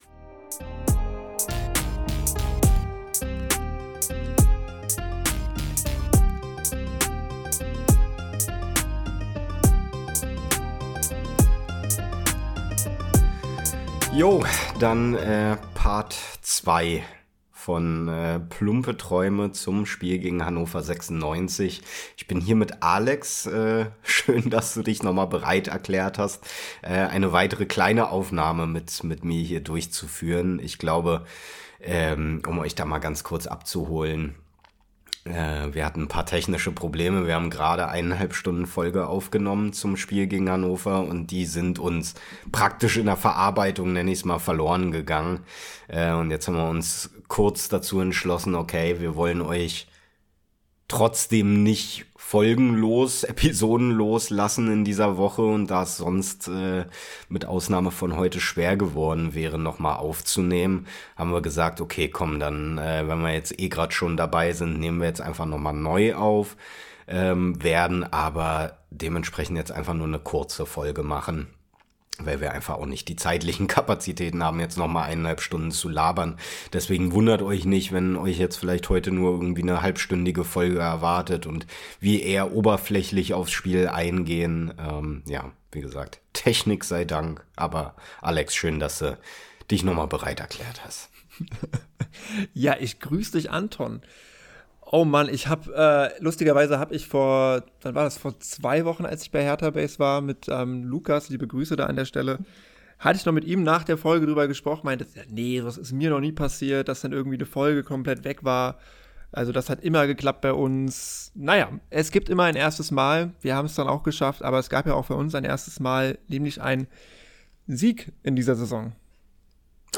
Leider hat es uns es die erste Spur komplett zerschossen wie Winkler das Tor in der 51. Minute.